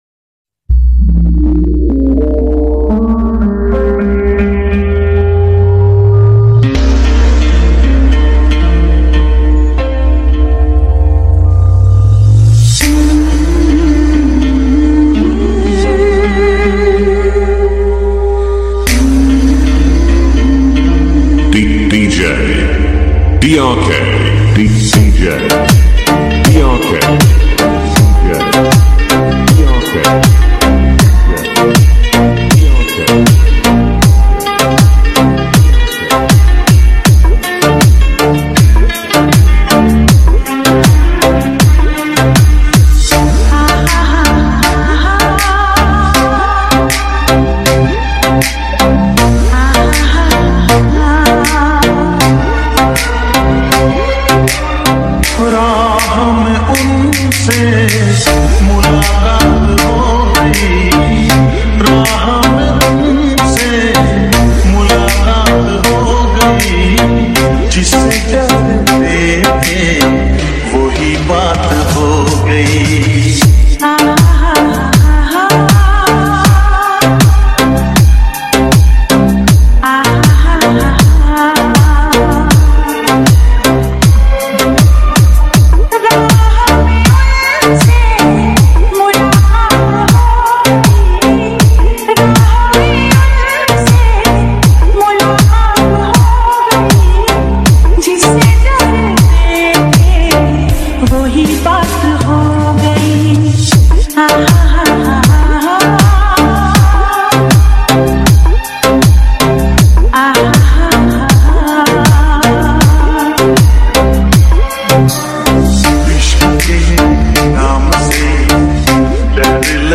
Urdu DJ Remix songs